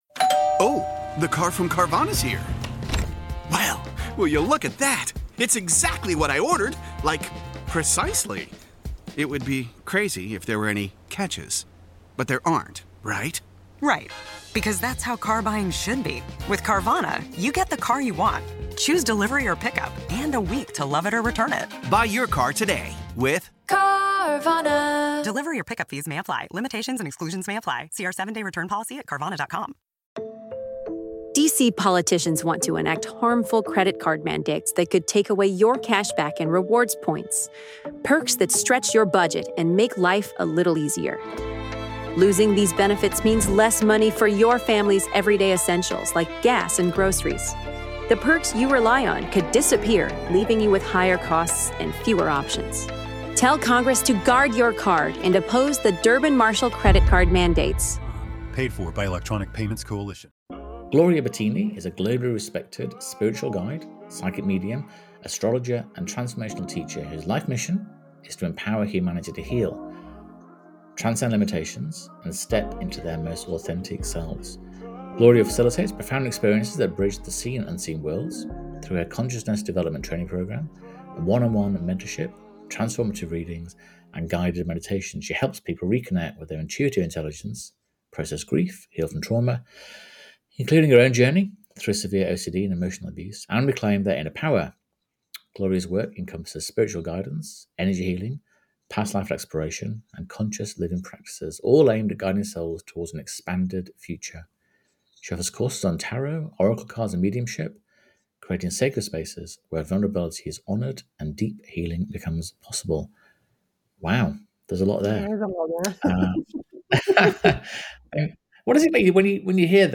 Empowering Your Intuitive Self: A Conversation with Spiritual Guide